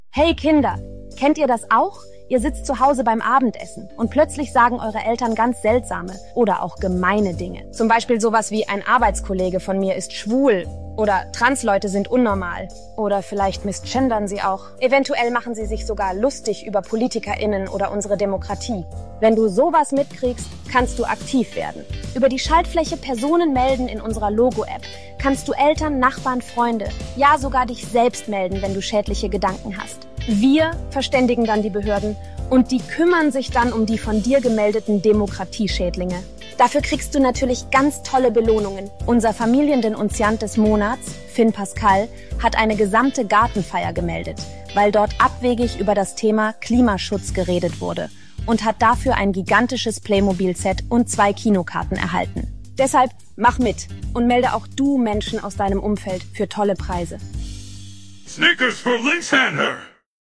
Weil vieles in diese Richtung bereits umgesetzt ist oder wird, wie die sogenannte Sexismus Meldestelle oder Handyapps zum Falschparker melden, sollte einem dieser Ausschnitt aus der ZDF-Kindersendung logo durchaus zu denken geben: